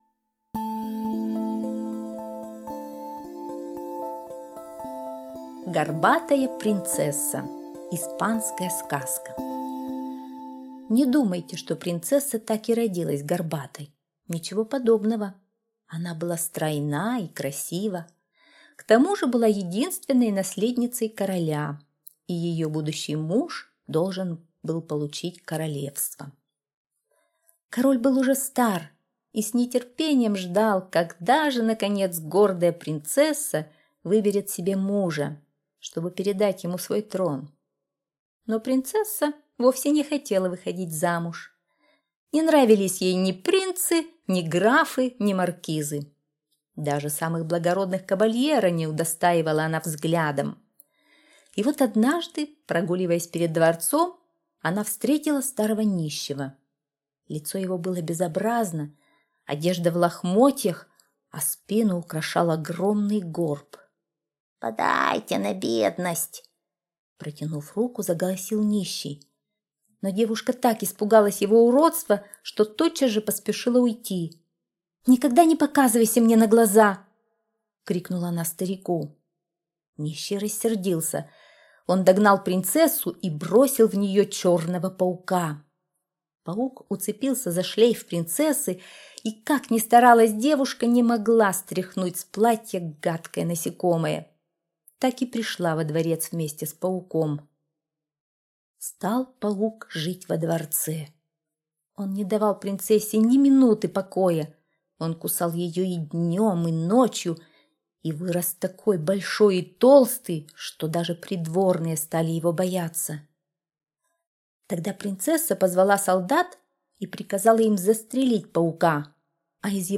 Горбатая принцесса - испанская аудиосказка - слушать онлайн